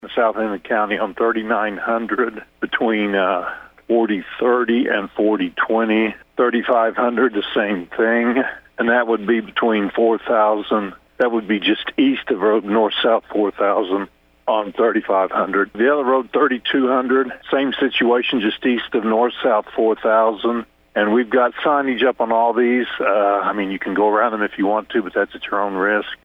District Three Commissioner for Washington County, Mike Dunlap says in the southern part of the county several roads are closed and he